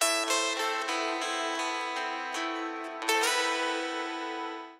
guitarriff.wav